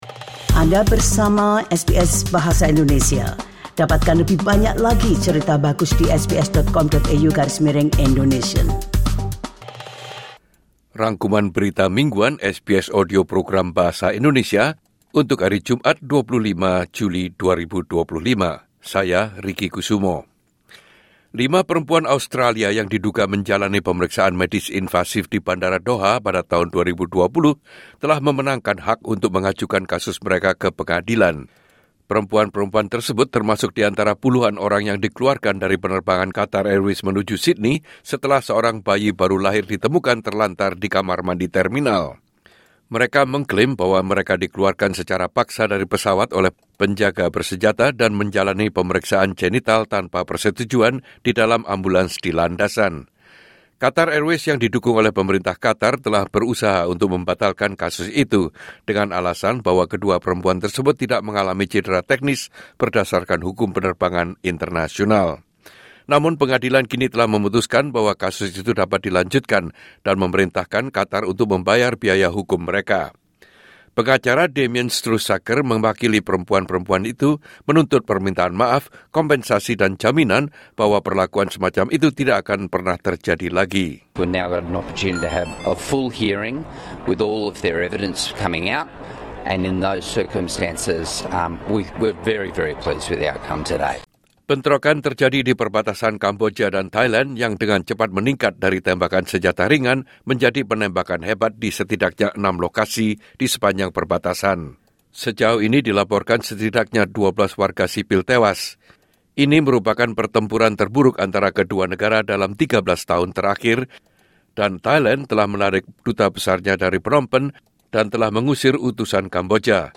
Rangkuman Berita Mingguan SBS Audio Progam Bahasa indonesia - Jumat 25 Juli 2025